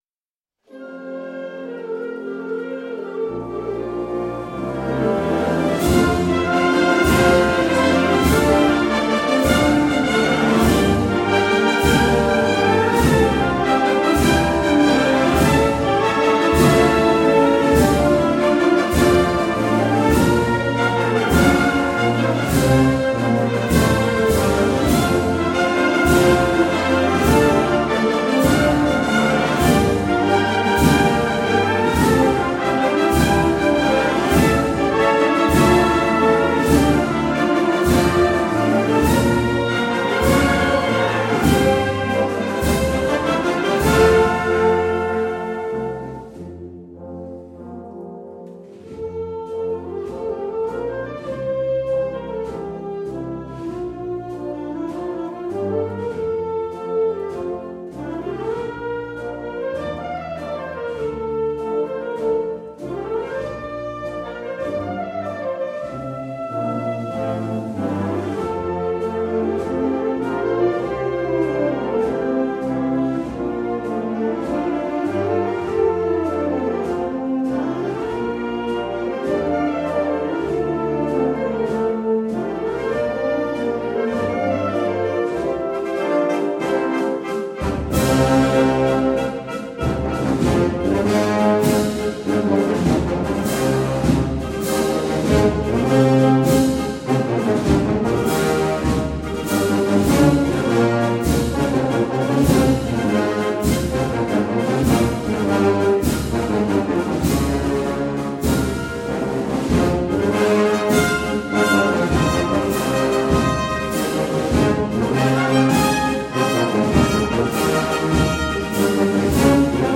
XXXIV acto de Exaltación a Nuestra Señora de la Encarnación
Sones de la marcha «
A tus pies, Encarnación» compuesta en 2019 por Pablo Perea Garrido e interpretada por la Banda de la Puebla dio paso al inicio de la Exaltación.